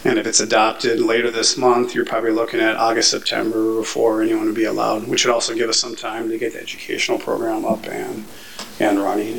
However, City Administrator Matt Jaunich says it will be a while until residents can actually have the birds: